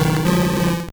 Cri de Kicklee dans Pokémon Or et Argent.